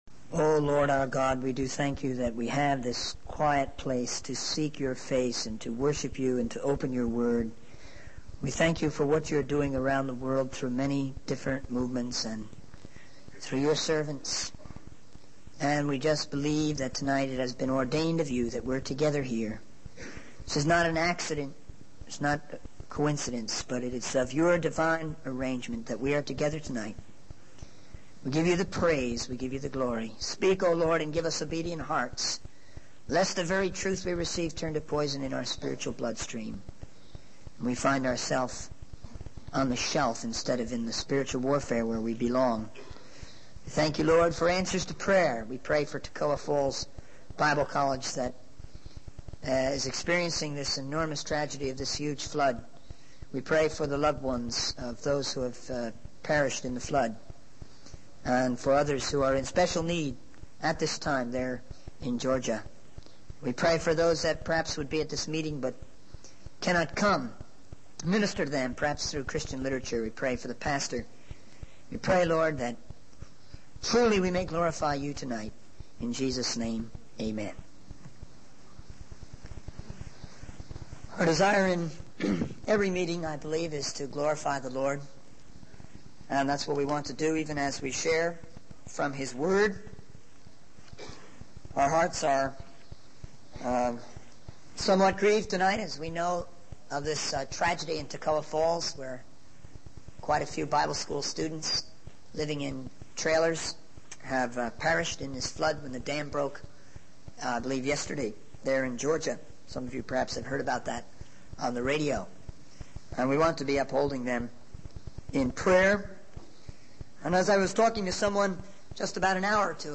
In this sermon, the speaker emphasizes the importance of discipleship, spiritual reality, and missions in the context of the local church.